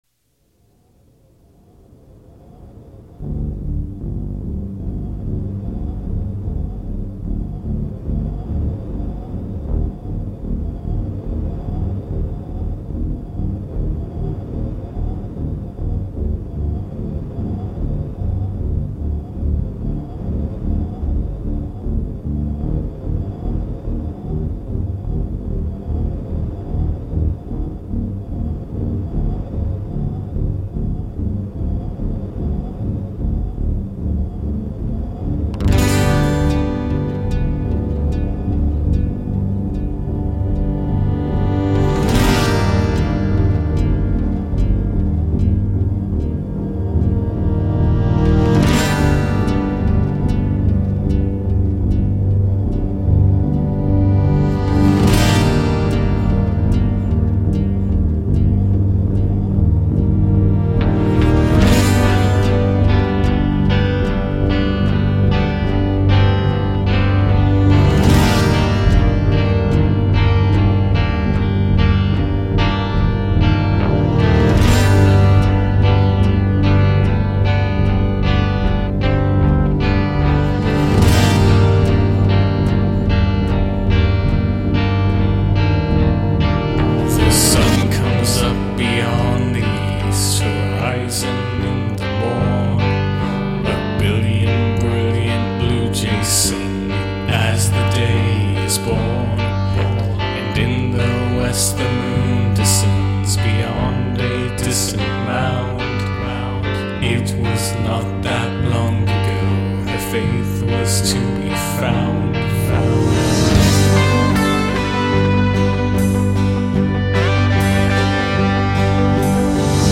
This one definitely has some Pink Floyd in it!